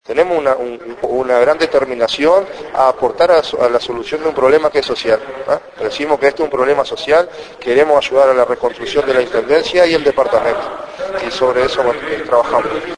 Coutinho asumió parte de la responsabilidad en diálogo con El Espectador.